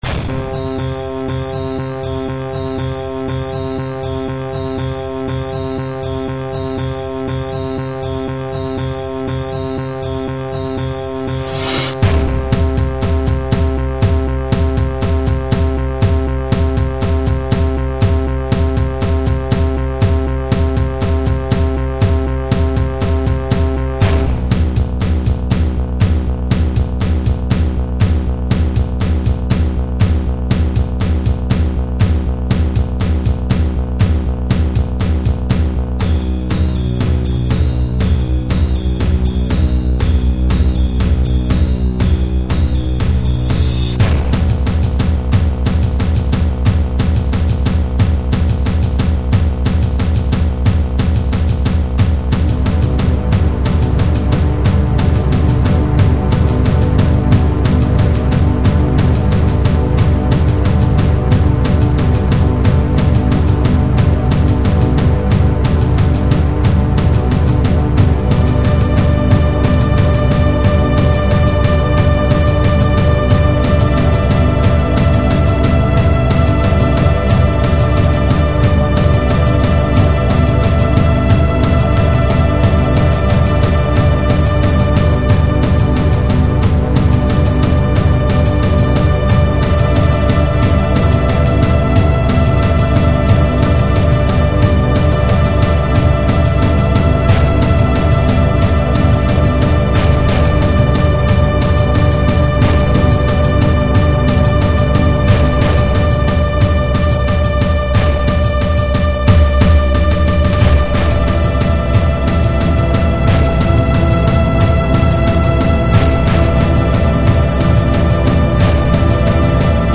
File under: Ambient / Industrial / Harsh Electronics
more layered and rhythmically structured